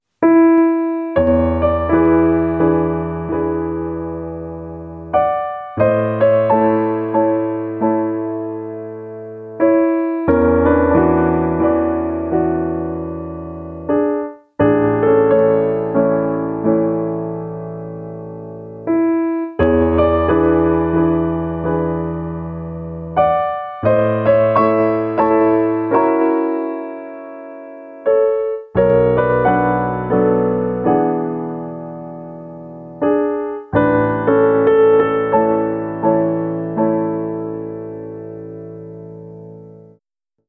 This is Prelude No 7 by Chopin. (The low fidelity means we can't hear him turning in his grave!)